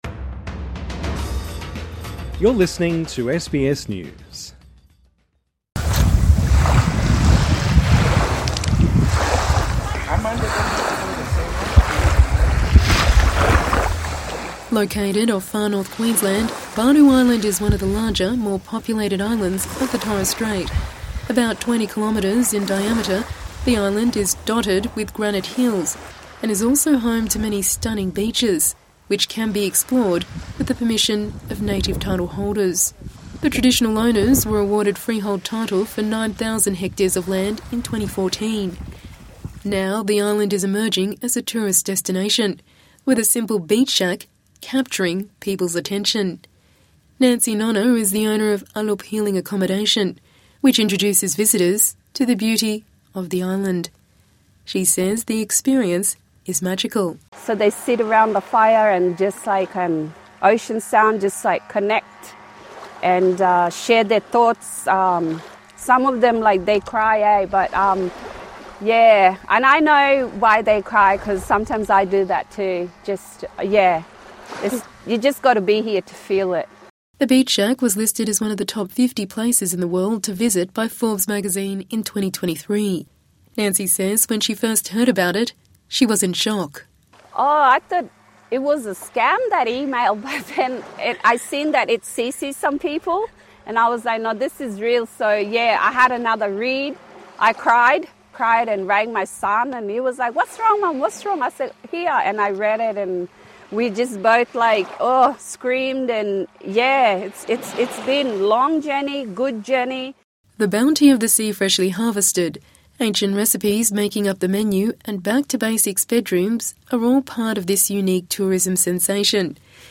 TRANSCRIPT (Sounds of waves washing away sand at the beach) Located off Far North Queensland, Badu Island is one of the larger, more populated islands of the Torres Strait.